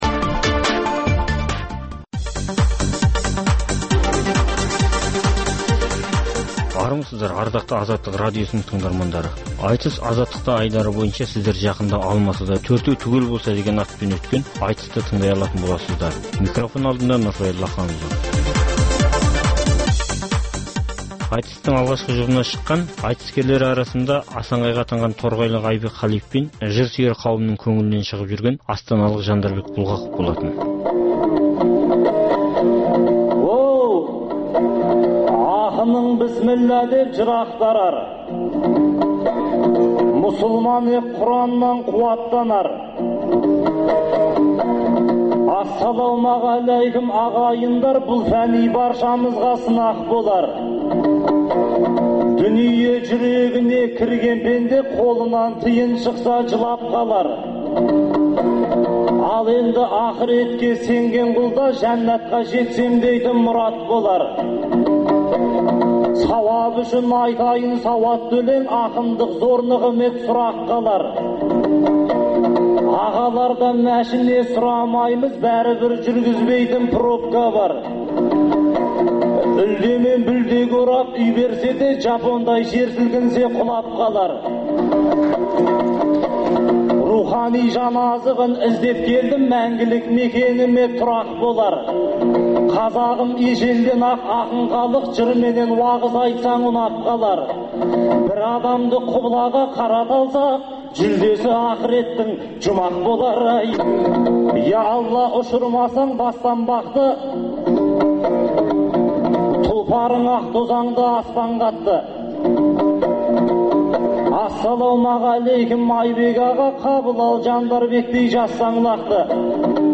Қазақстанда әр уақытта өткізілетін ақындар айтысының толық нұсқасын ұсынамыз.